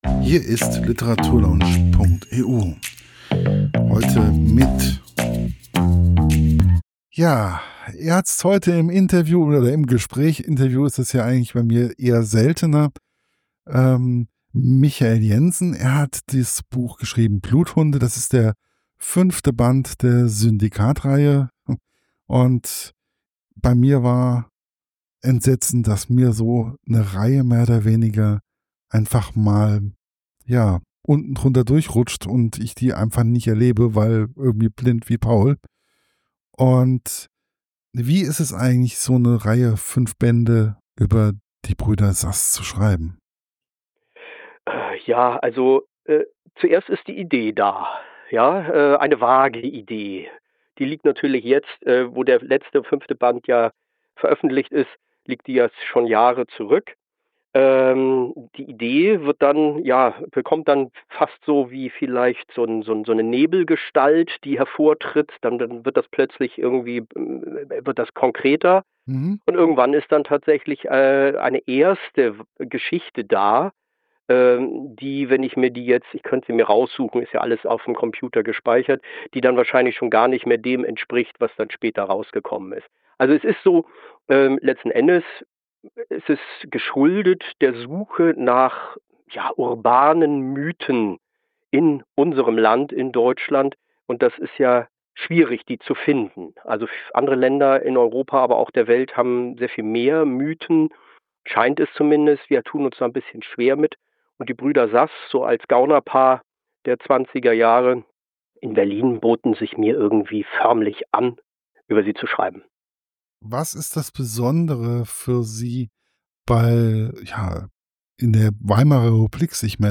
BluthundeInterview.mp3